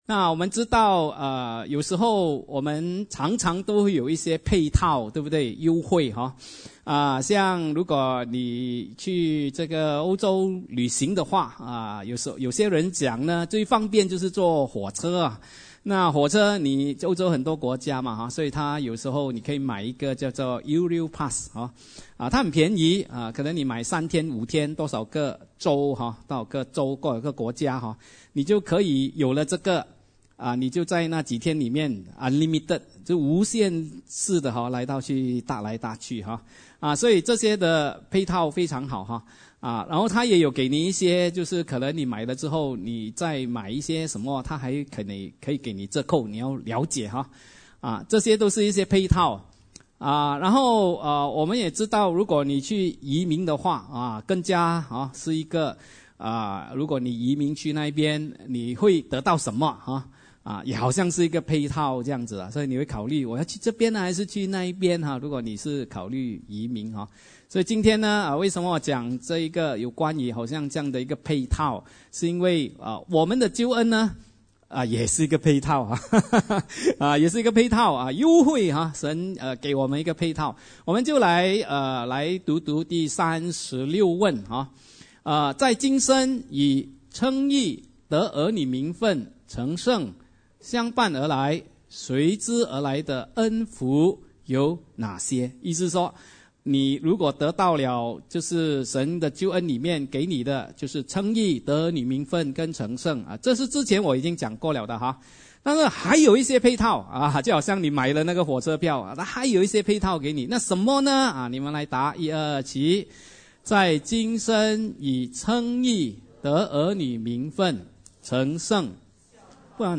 Sunday Service Chinese